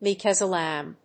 アクセント(as) méek as a lámb